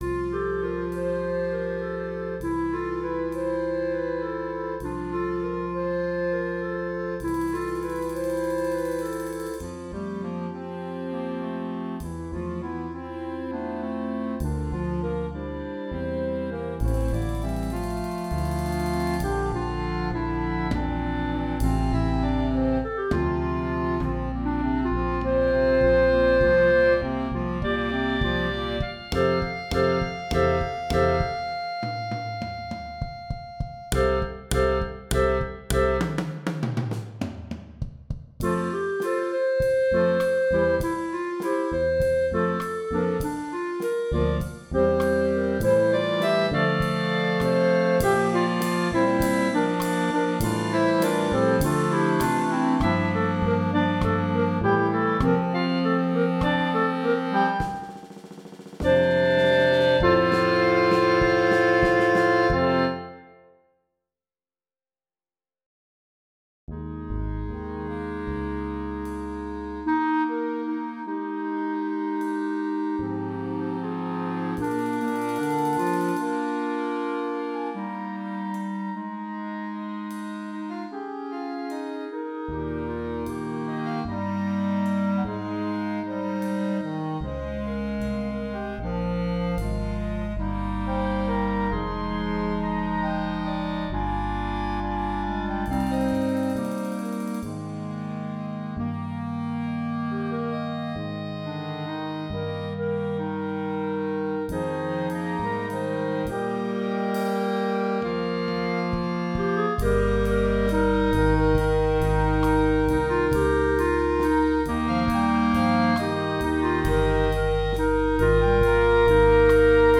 3 trpt, 2 Hn, 2 Tbn, Euph, Tuba
Brass Ensemble